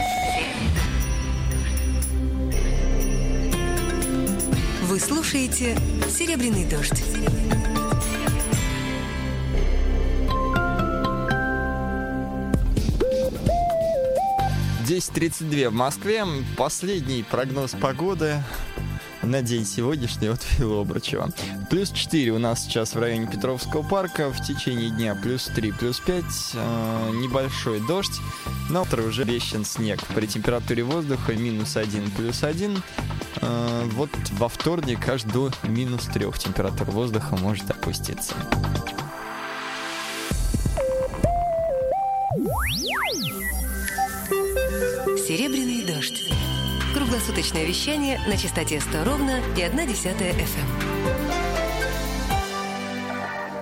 Прогноз погоды (Серебряный дождь, 17.12.2006)